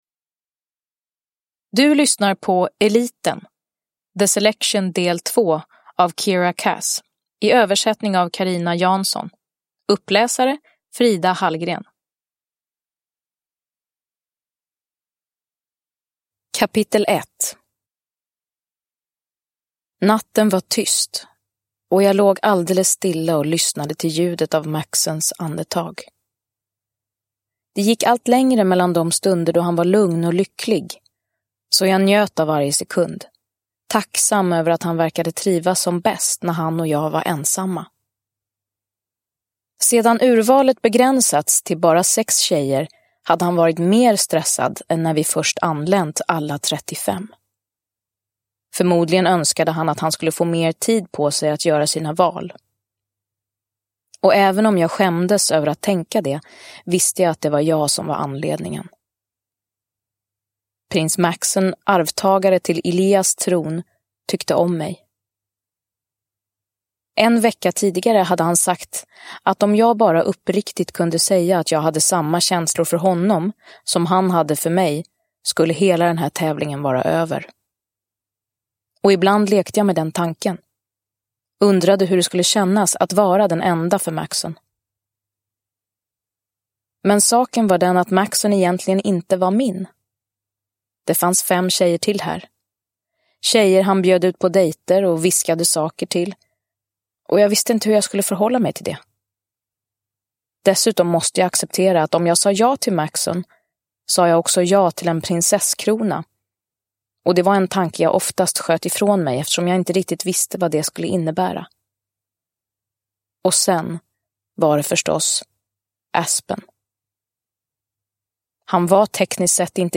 Eliten – Ljudbok – Laddas ner
Uppläsare: Frida Hallgren